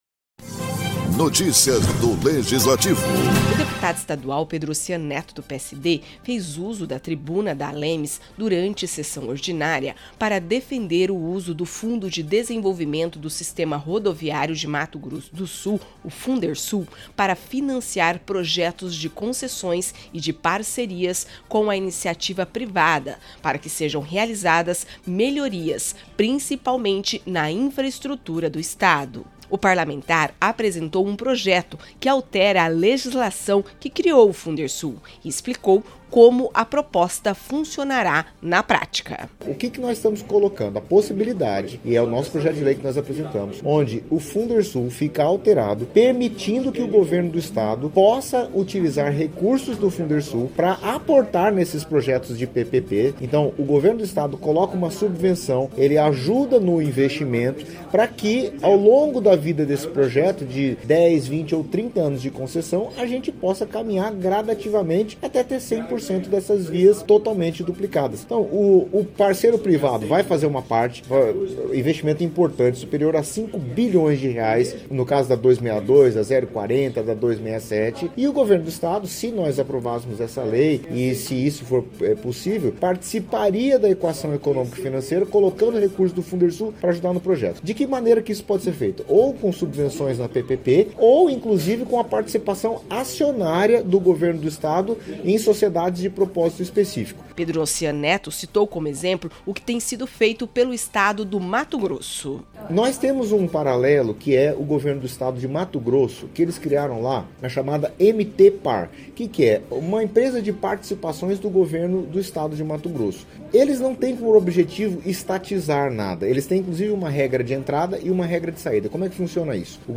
O deputado estadual Pedrossian Netto (PSD) fez uso da tribuna, durante a sessão plenária, para defender o uso do Fundo de Desenvolvimento do Sistema Rodoviário de Mato Grosso do Sul, o Fundersul, para financiar projetos de concessões e de parcerias com a iniciativa privada para que sejam realizadas melhorias principalmente na infraestrutura do Estado.